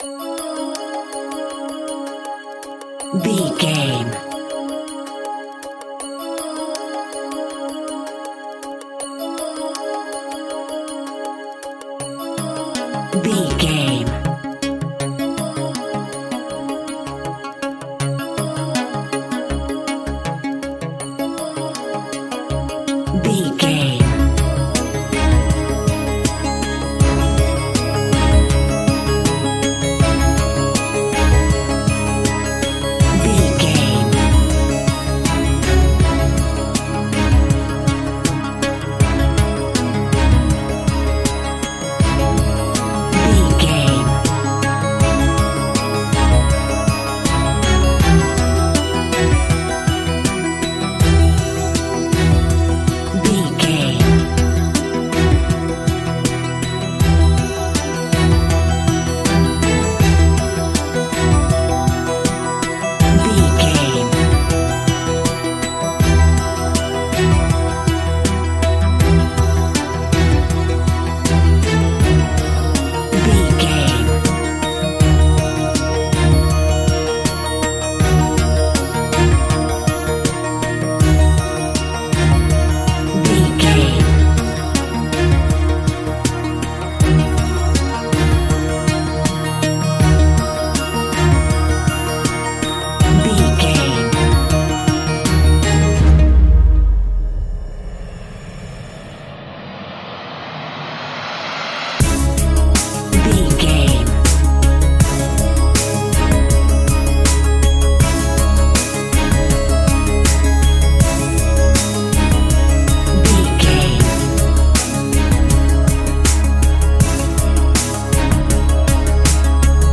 Ionian/Major
C♯
electronic
techno
trance
synths
synthwave